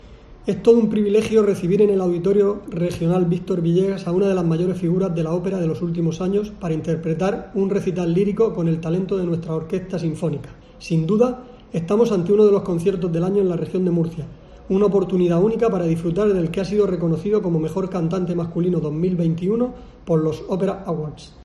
José Ramón Palazón, director general del ICA